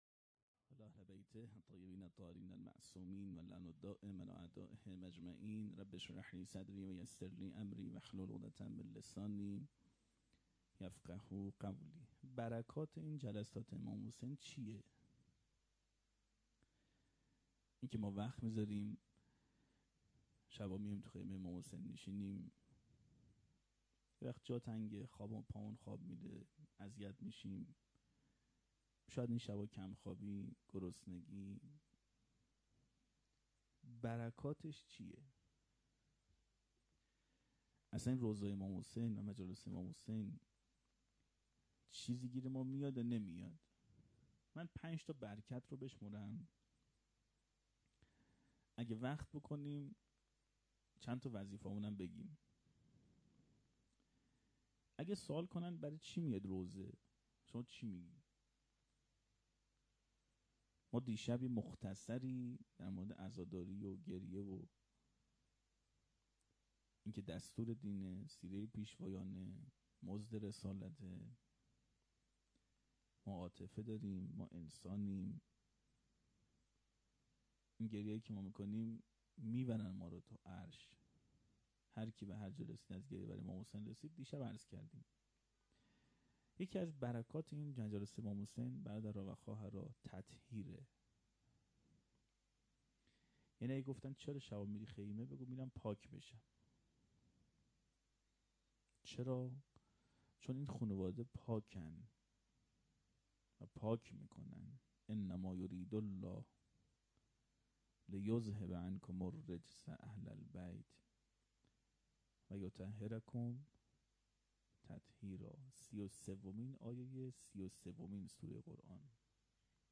سخنرانی.mp3